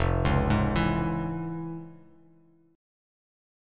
FmM7#5 chord